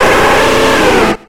Cri de Feunard dans Pokémon X et Y.